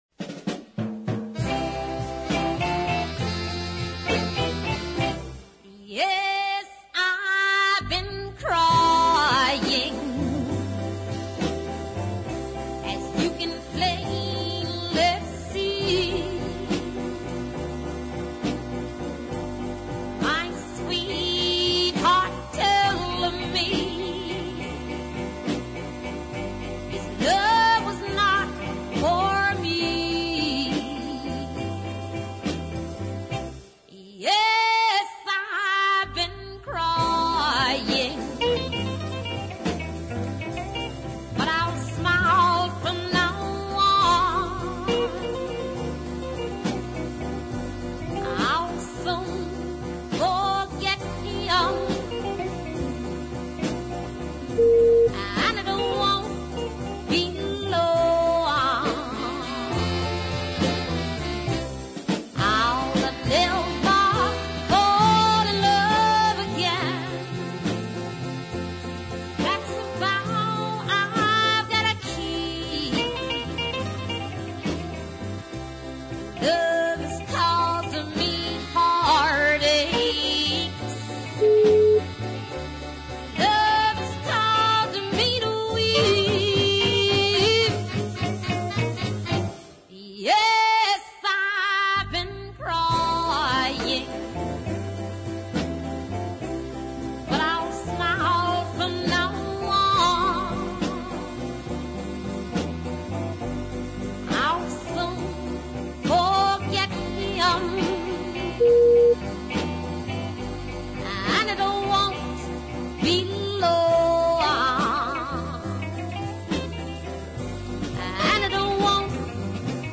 Much better is the slow
a blues ballad with a lot of charm
I like the guitarist a lot, as well as the horn charts